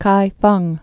(kīfŭng)